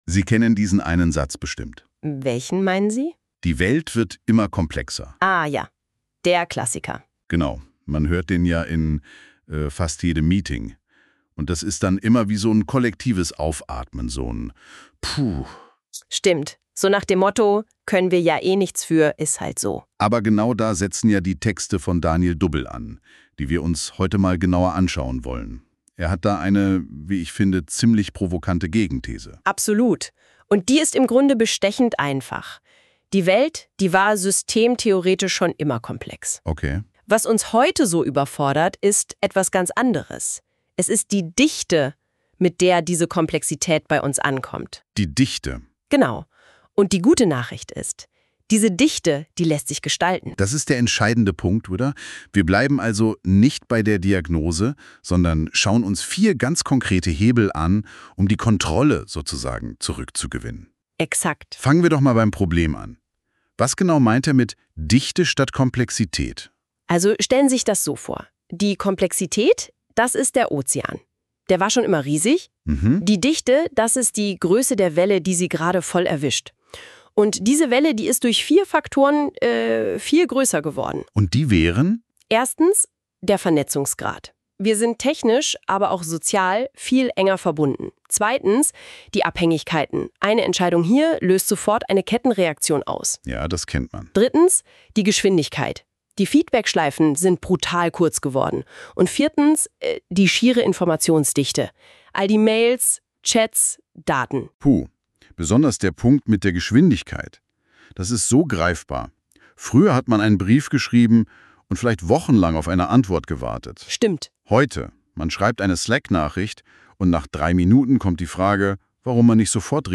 Hier bekommst du einen durch NotebookLM generierten KI-Podcast Dialog zu diesem Artikel.